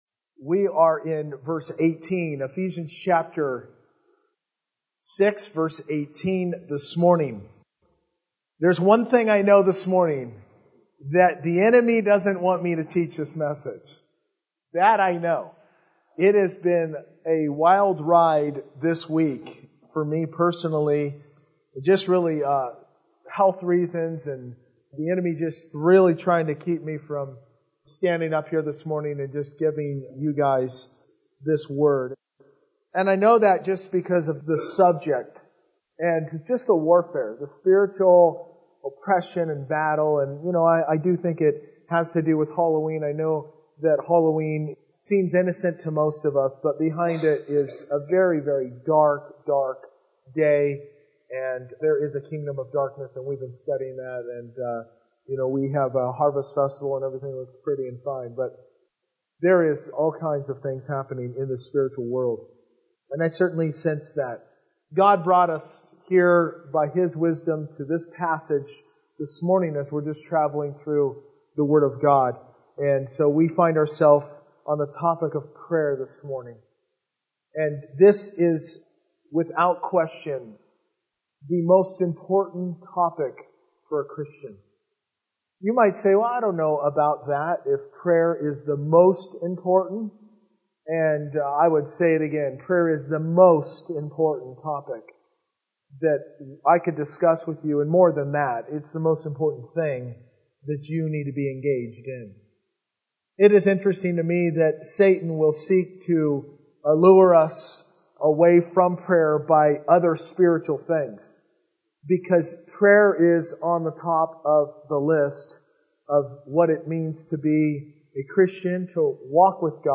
Verse by Verse-In Depth